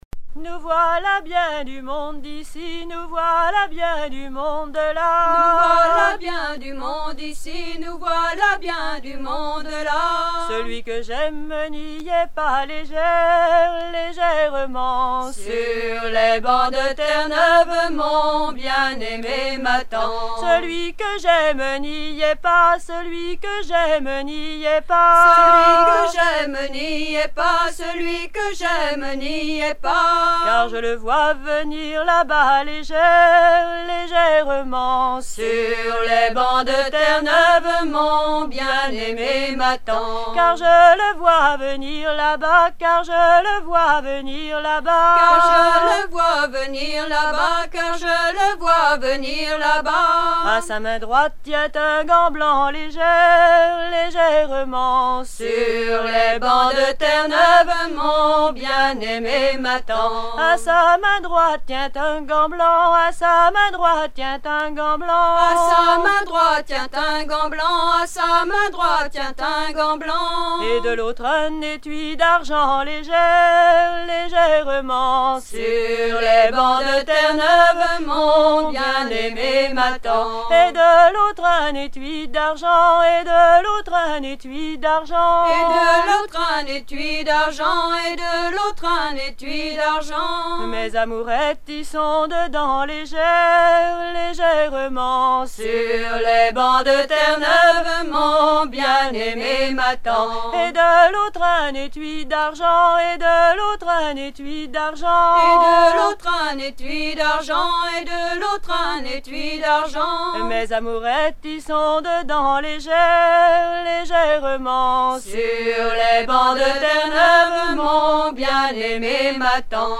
danse : ronde
circonstance : maritimes
Chants de marins traditionnels